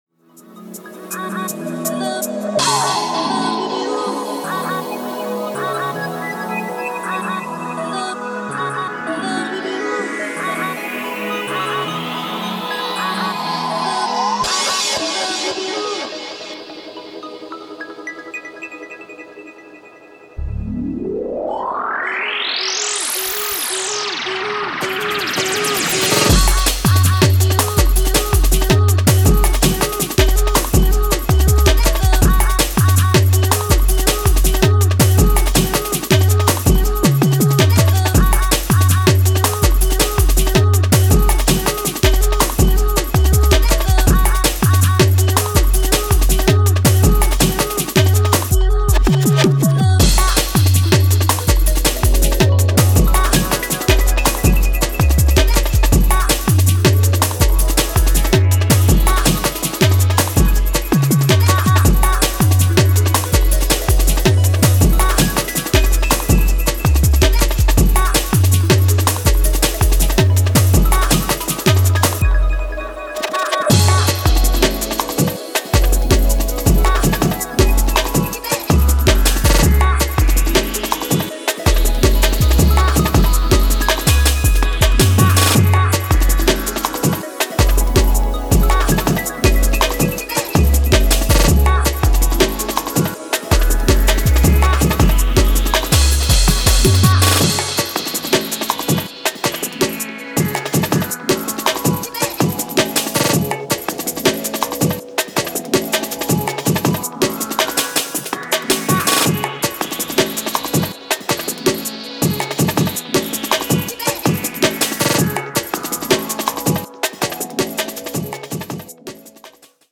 ビート/ベースの切れ味が増したアトモスフェリックな仕上げが素晴らしいです。